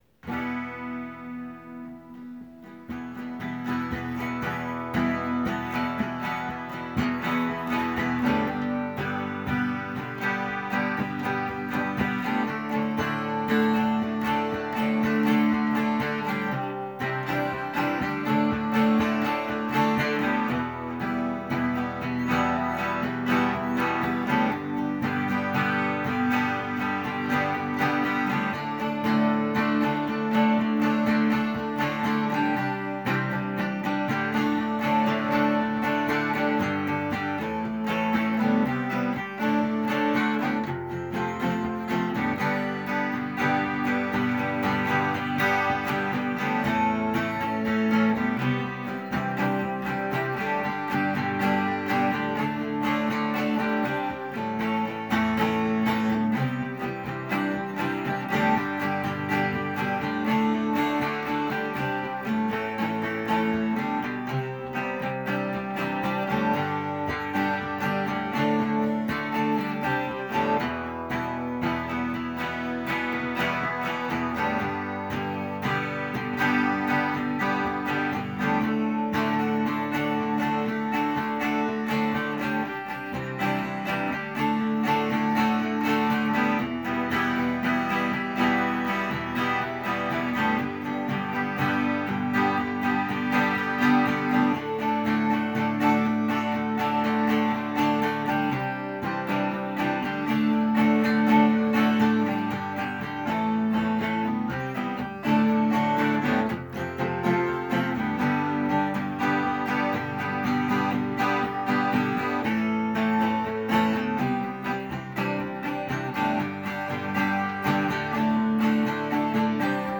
music_smorgasbord_duelforlove_acoustic.m4a